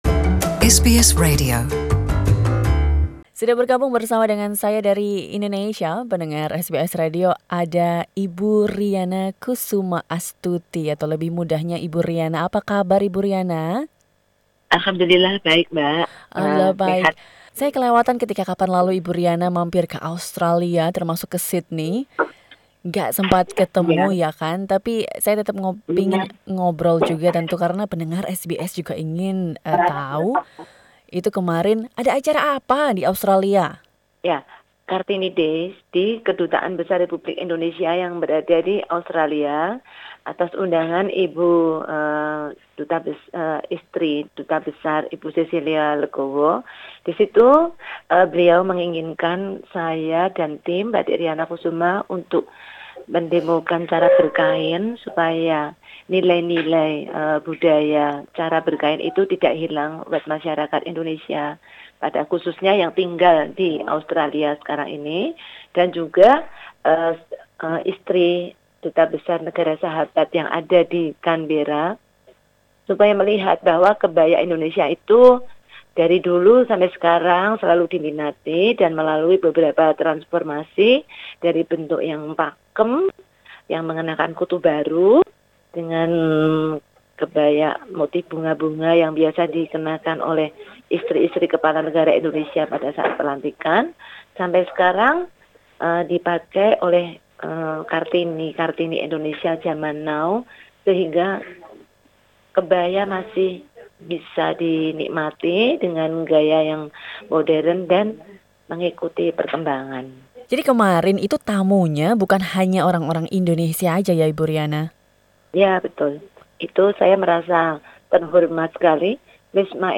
menangis ketika bercerita tentang bagaimana perjuangan panjang keluarganya di industri batik membuat perjalanan tersebut berharga.